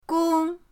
gong1.mp3